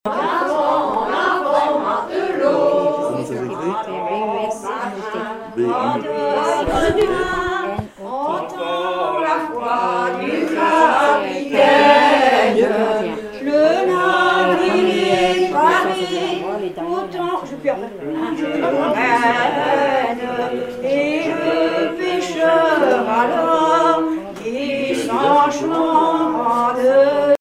Informateur(s) Club d'anciens de Saint-Pierre association
Genre strophique
Pièce musicale inédite